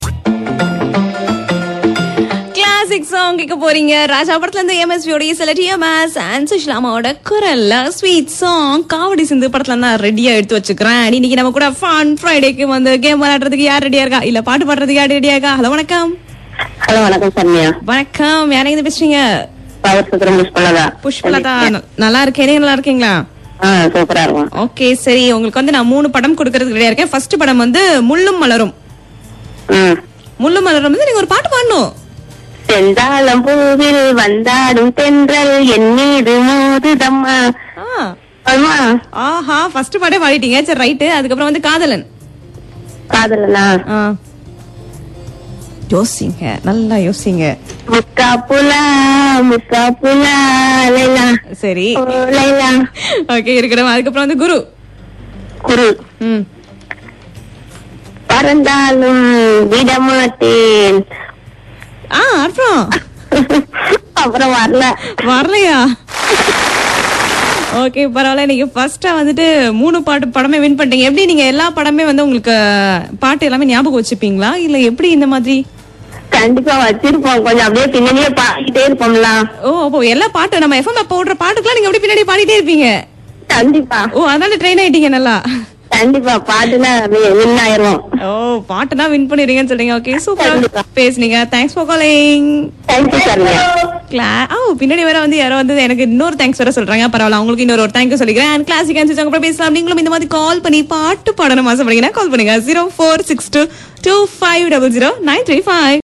game for listerner ...singing song for a topic